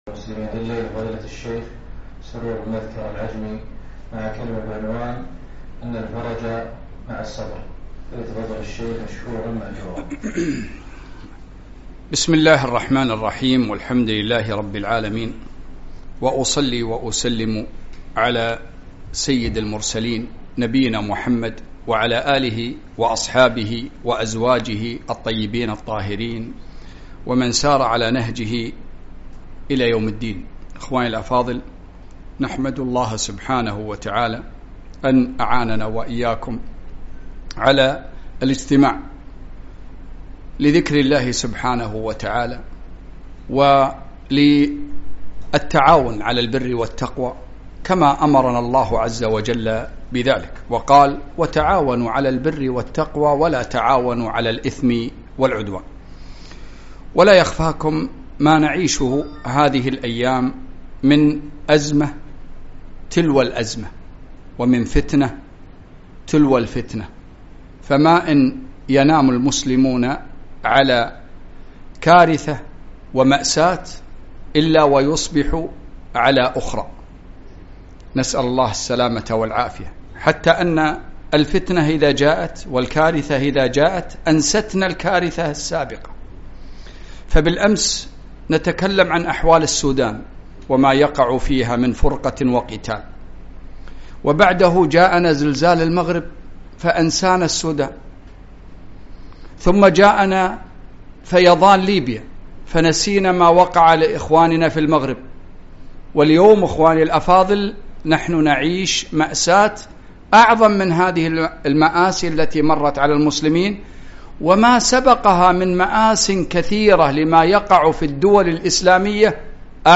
محاضرة - وأعلم أن الفرج مع الصبر